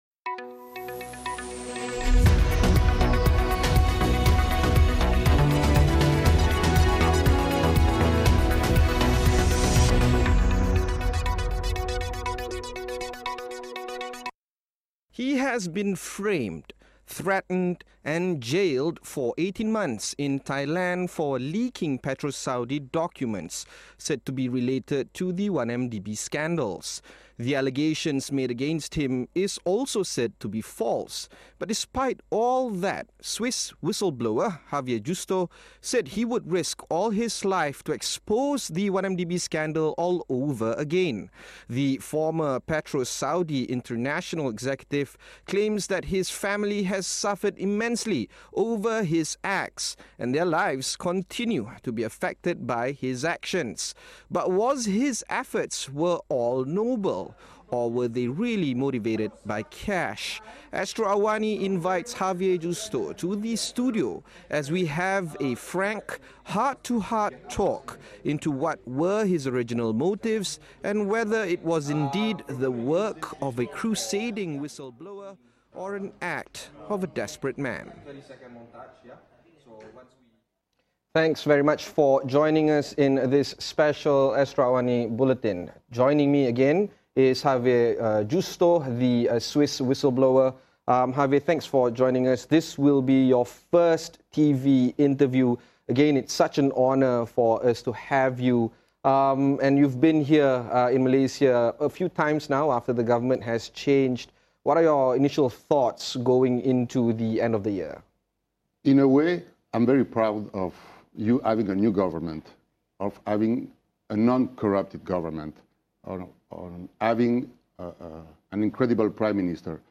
Xavier Justo gives his first-ever televised interview